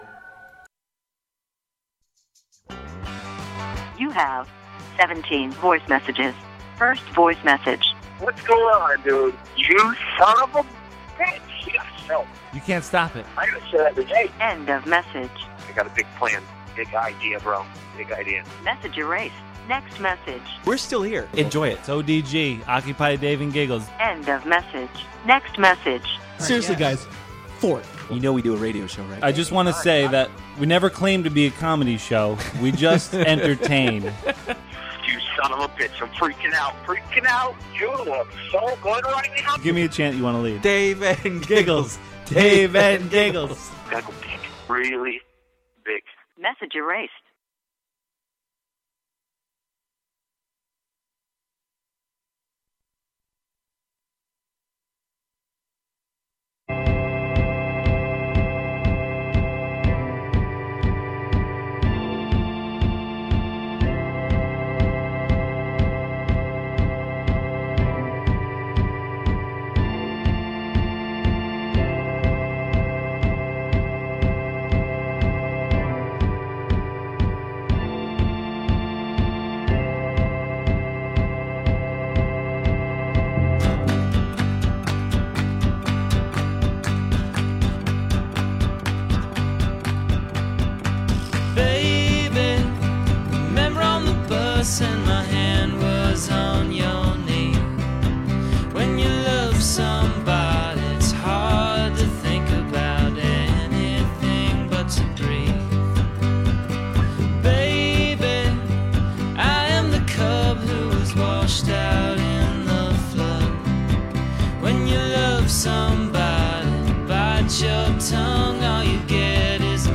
we have guests, and sometimes just ourself and a caller from time to time.